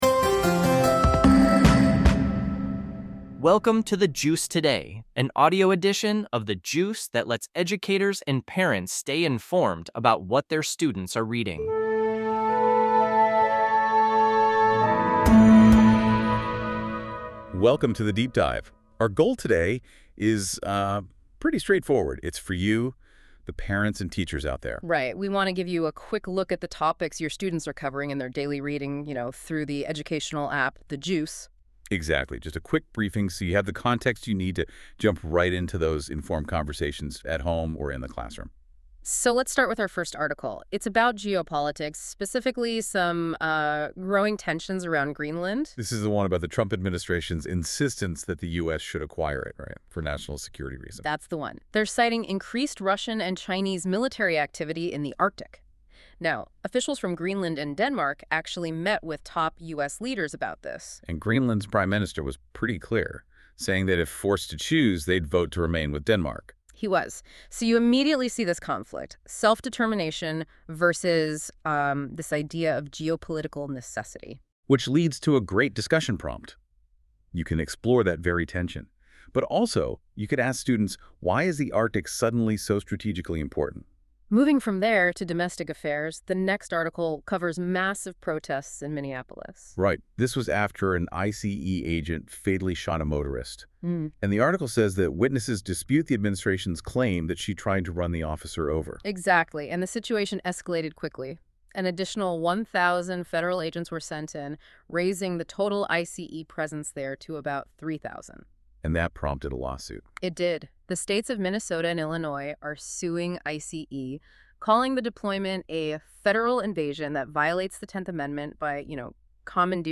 This podcast is produced by AI based on the content of a specific episode of The Juice.